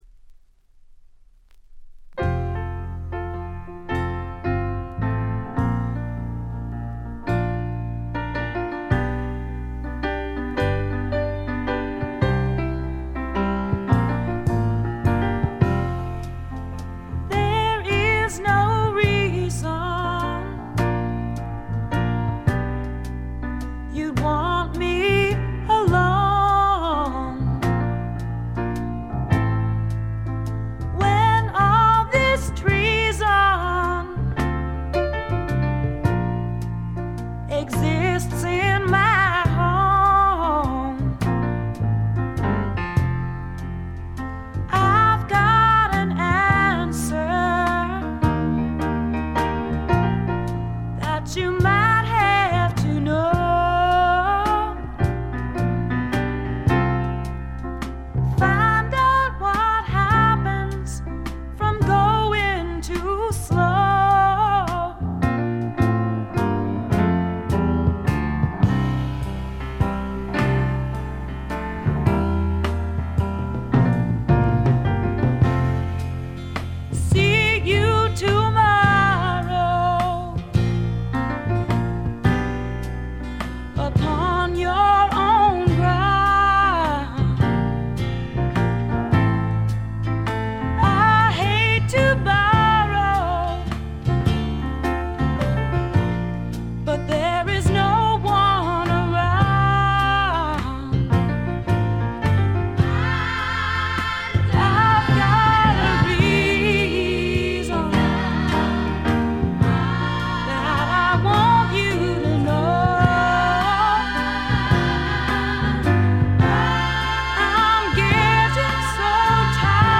少しコケティッシュで、ソウルフルで、実に魅力的なヴォイスの持ち主。女性スワンプの大傑作です。
試聴曲は現品からの取り込み音源です。